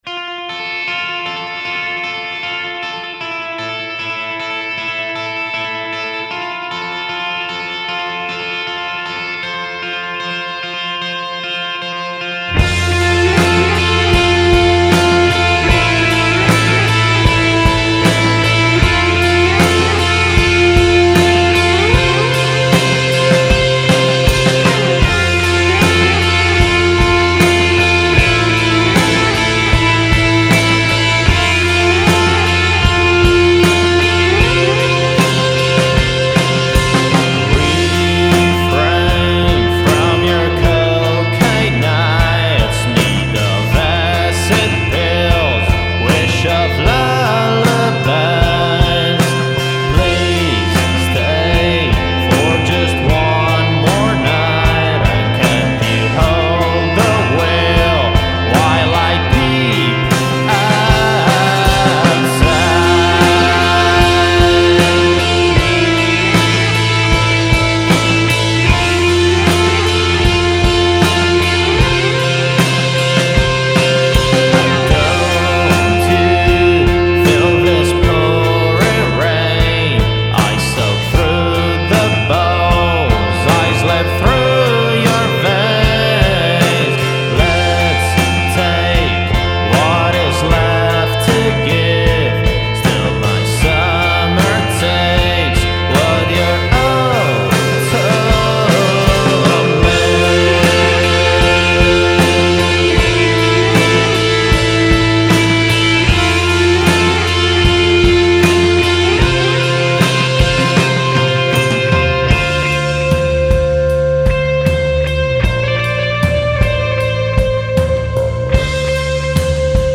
GenerePop